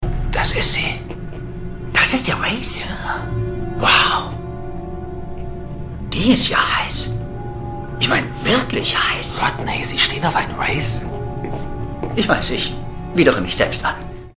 McKay und Sheppard sprechen über den Wraith an Bord der Aurora.